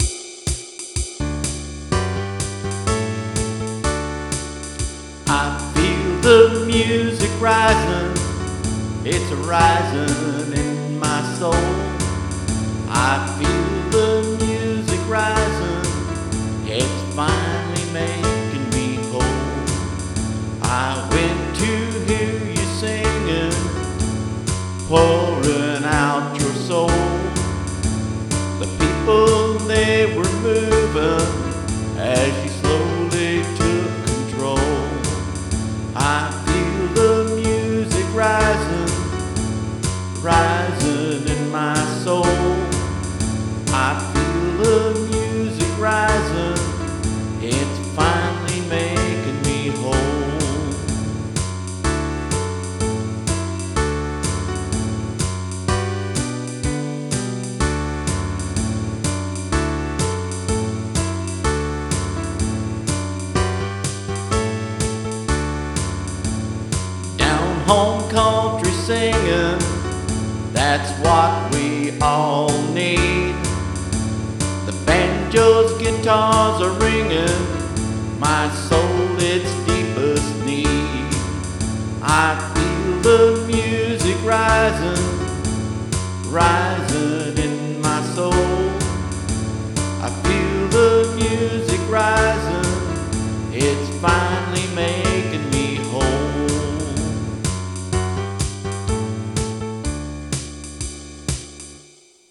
IT's a SOUL Thing.